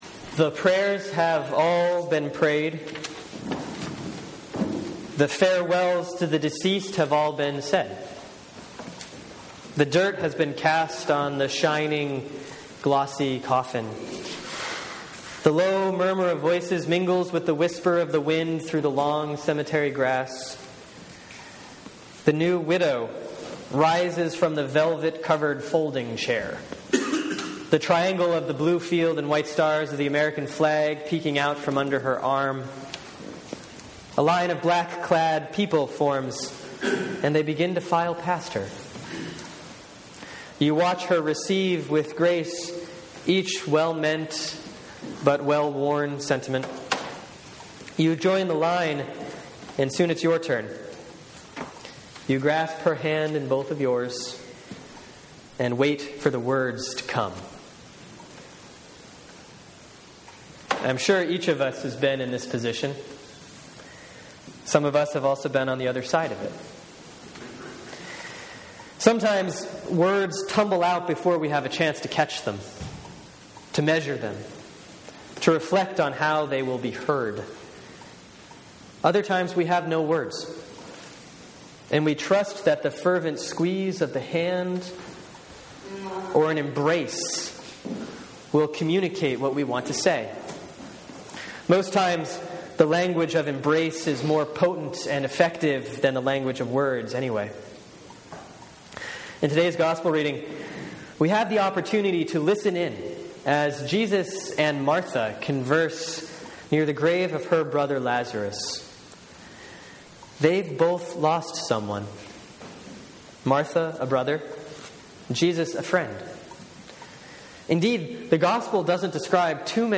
Sermon for Sunday, April 6, 2014 || Lent 5A || John 11:1-44